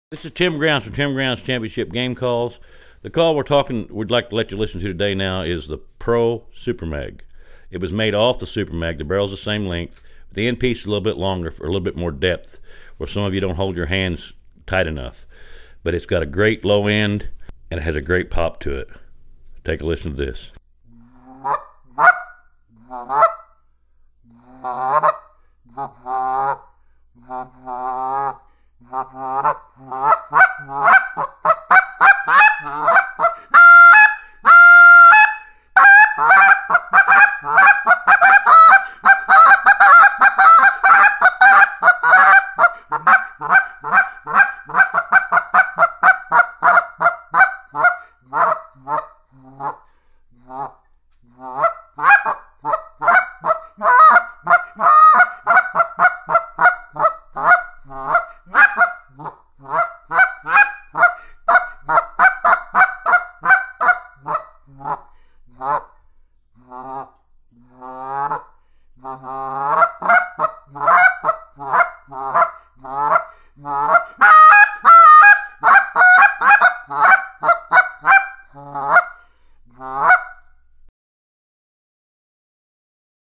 Goose Calls
This insert enables the caller to create less back pressure.
It has a great, quick reed response. It will go from the deepest guttural laydowns to the most powerful triple clucks.